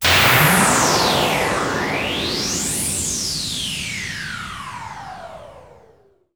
FIZZLEHIT.wav